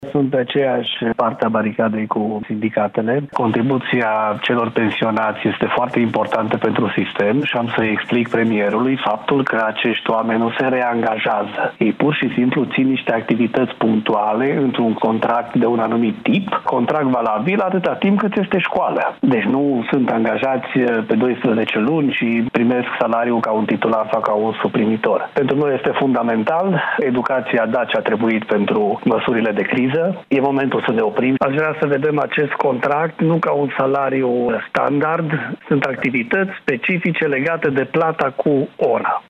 Ministrul Educației, Daniel David, spune că va discuta cu premierul pentru ca interdicția de a cumula pensia cu salariul să nu se aplice și în învățământ. Ministrul a explicat la B1TV că sunt câteva mii de profesori ieșiți la pensie care predau în prezent și că aceștia nu primesc același salariu ca dascălii titulari, ci sunt încadrați la plata cu ora.